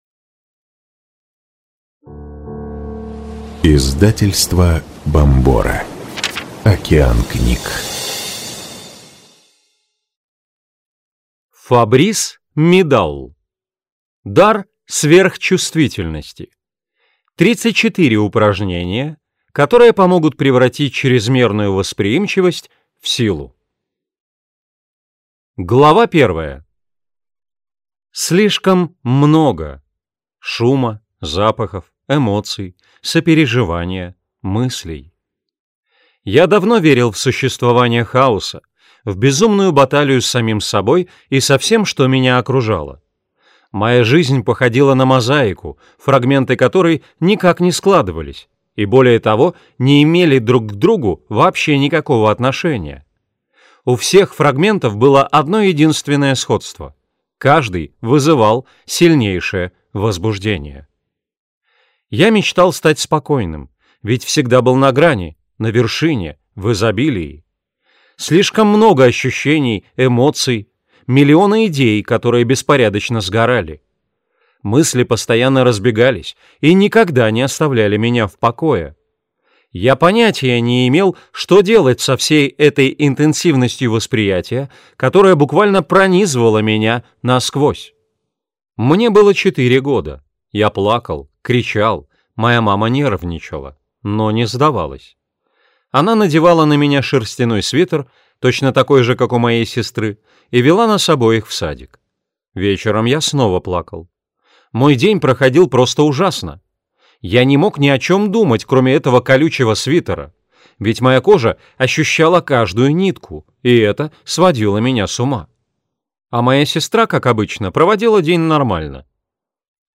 Аудиокнига Дар сверхчувствительности. 34 упражнения, которые помогут превратить чрезмерную восприимчивость в силу | Библиотека аудиокниг